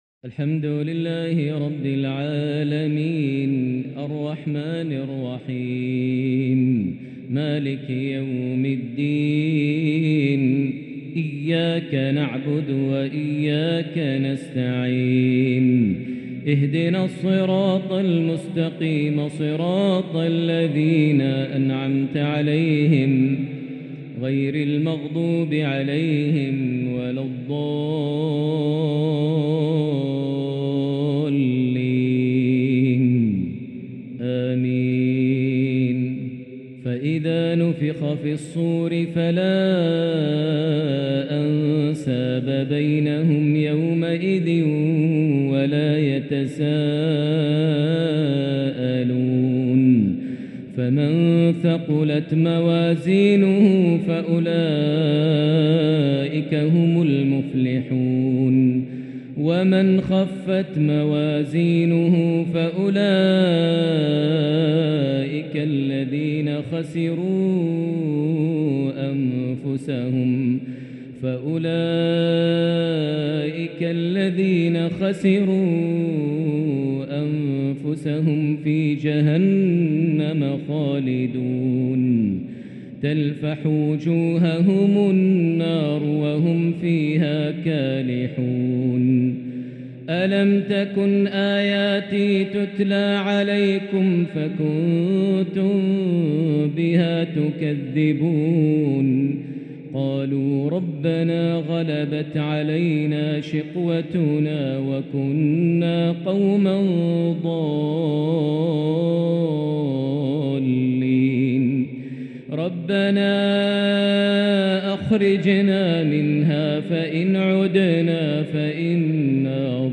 Isha prayer from Surah Al-mu’menoon 2-6-2023 > 1444 H > Prayers - Maher Almuaiqly Recitations